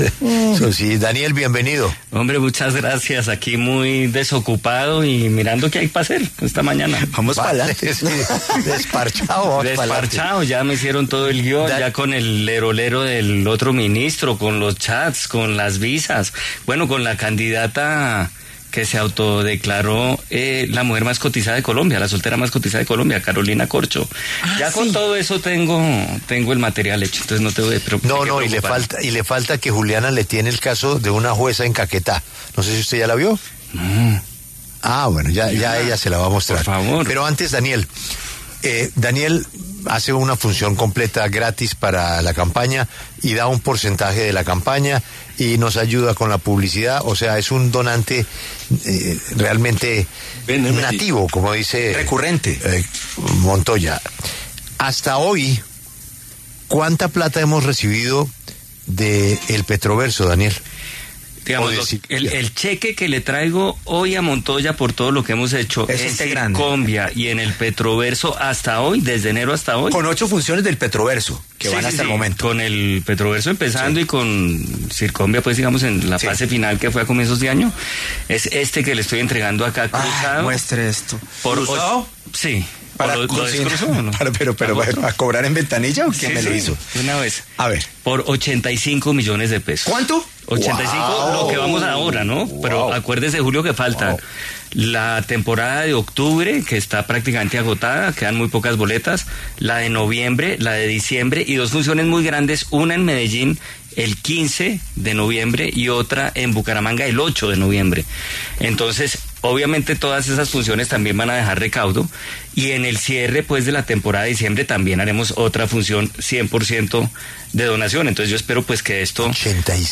Daniel Samper Ospina, periodista, conversó con La W sobre la campaña Pa’Lante 2025, en la que apoyará con su show del Petroverso.